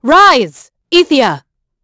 Isolet-Vox_Skill2_b.wav